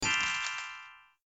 sparkly.ogg